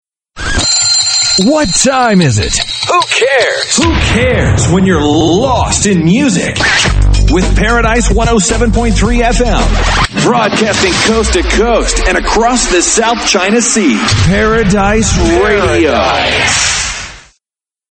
TOP 40